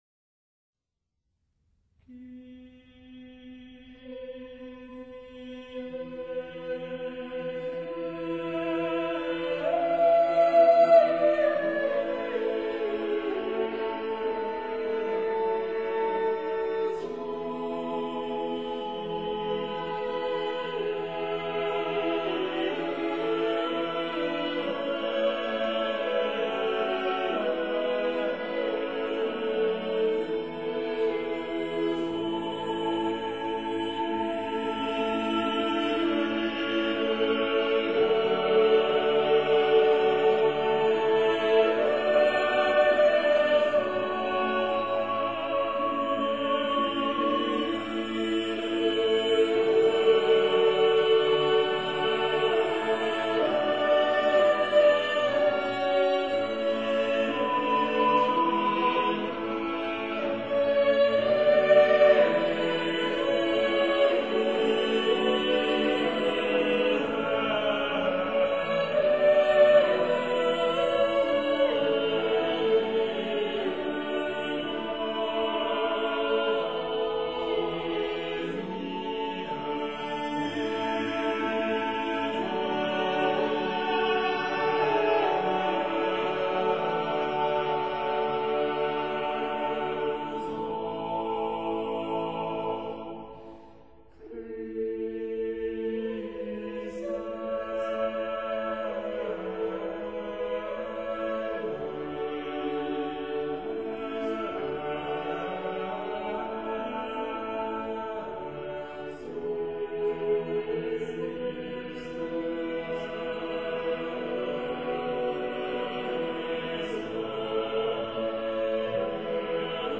无伴奏合唱（ a cappella）